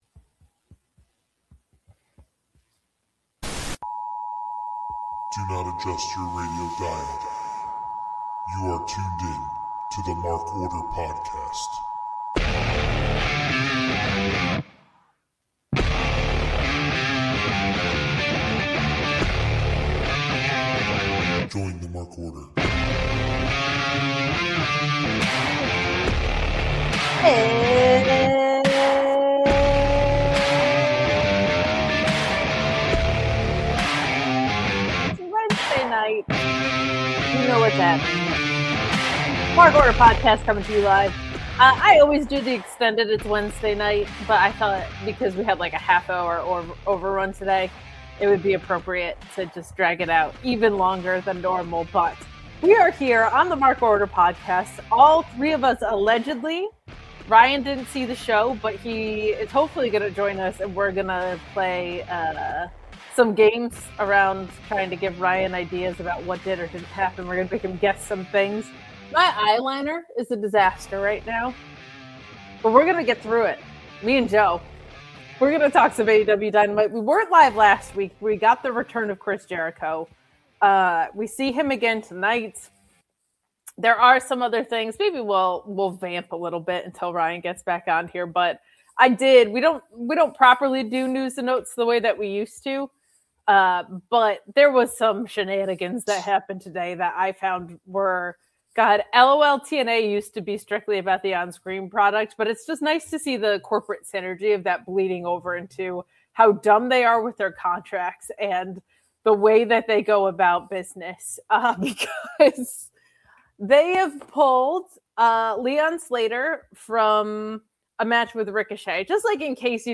All three members are on the pod tonight.